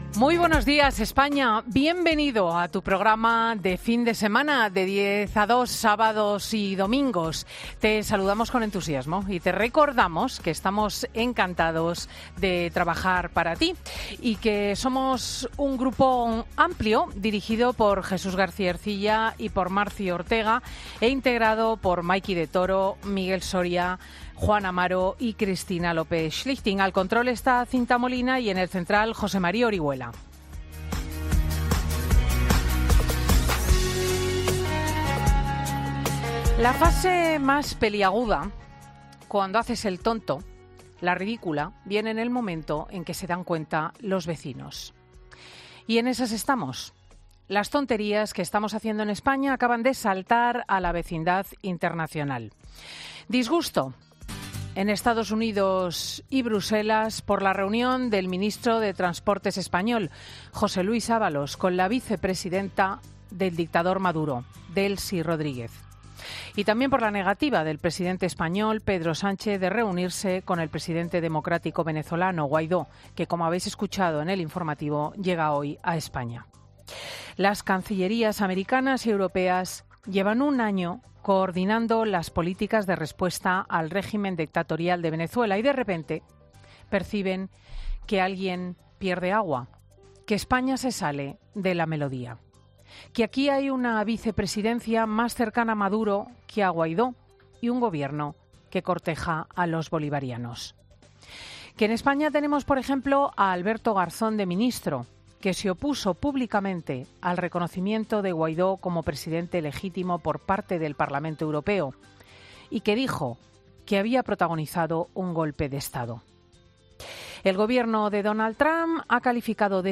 Monólogo de Cristina López Schlichting del sábado 25 de enero de 2020